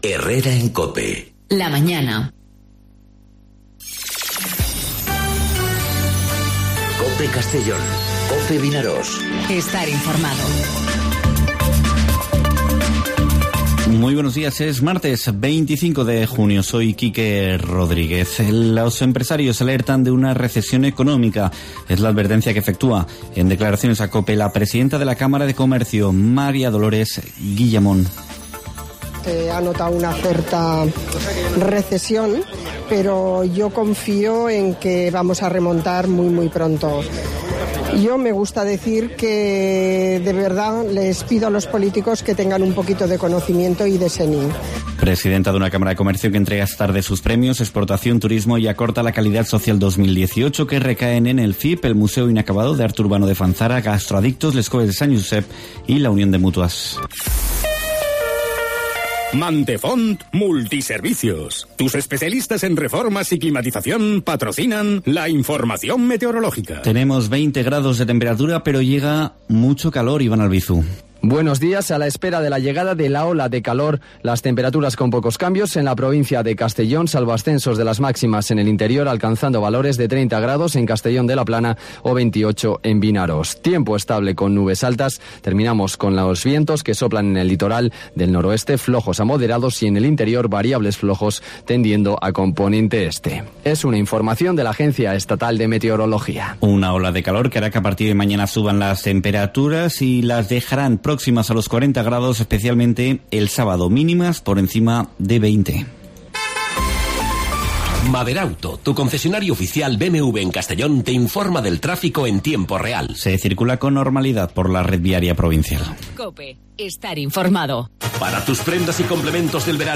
Informativo 'Herrera en COPE' en Castellón (25/06/2019)